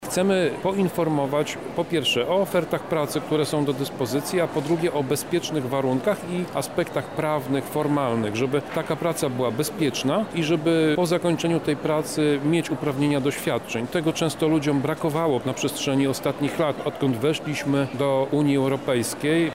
O celach wydarzenia mówi Dyrektor Wojewódzkiego Urzędu Pracy w Lublinie Tomasz Pitucha: